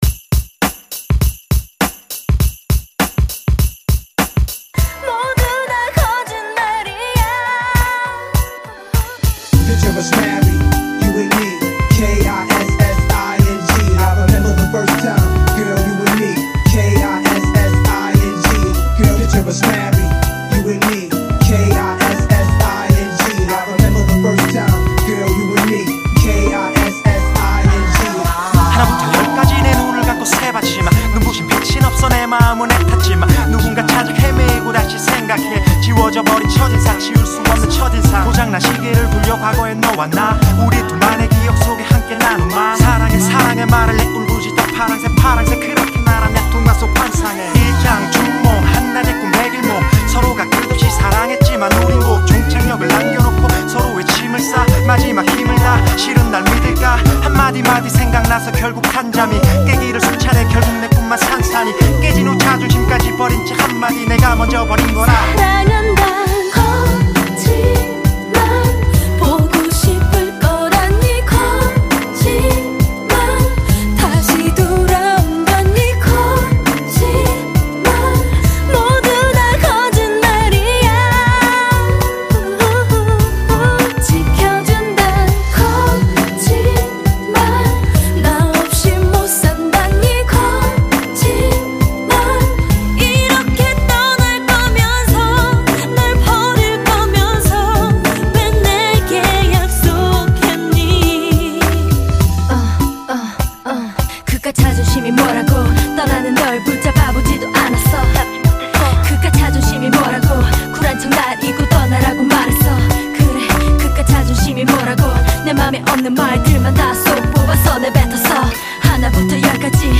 • [국내 / REMIX.]
(Slow version)